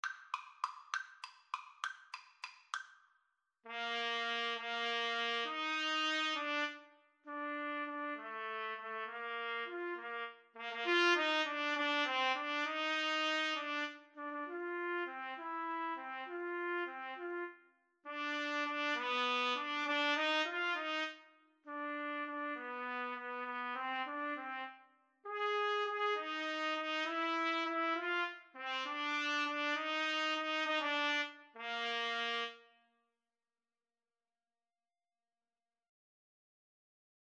Play (or use space bar on your keyboard) Pause Music Playalong - Player 1 Accompaniment reset tempo print settings full screen
3/8 (View more 3/8 Music)
Bb major (Sounding Pitch) C major (Trumpet in Bb) (View more Bb major Music for Trumpet Duet )